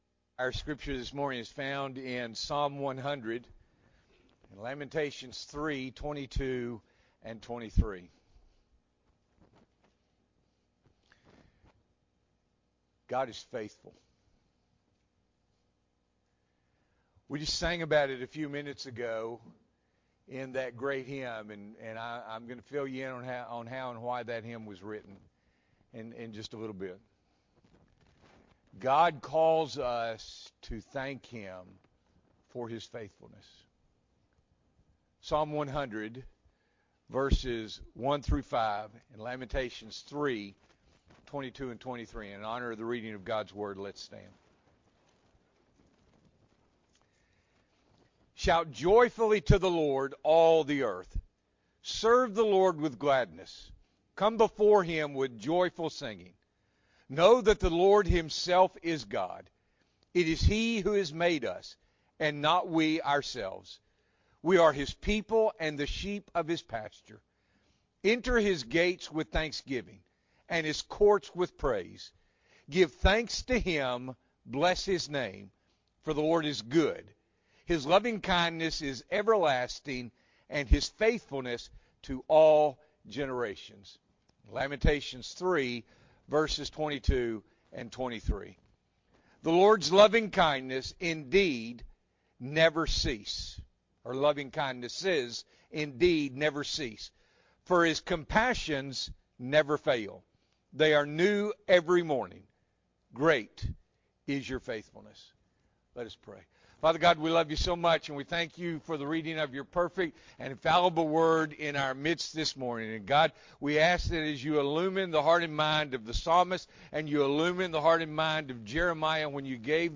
November 19, 2023 – Morning Worship